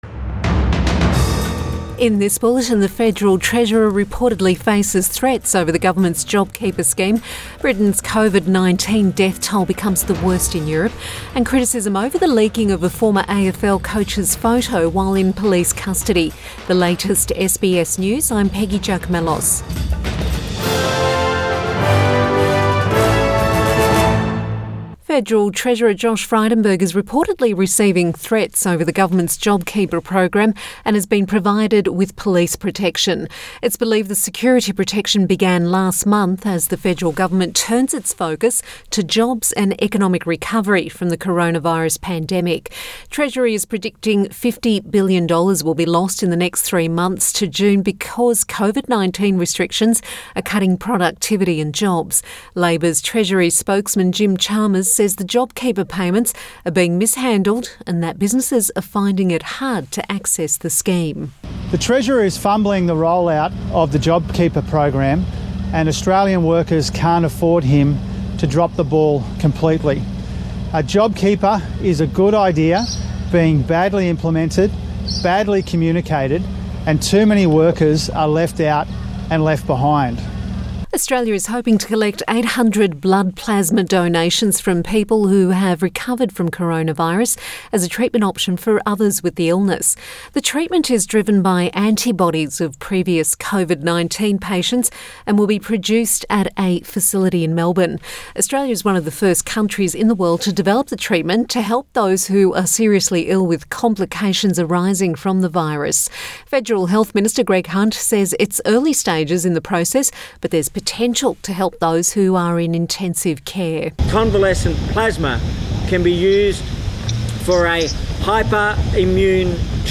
Midday bulletin May 6 2020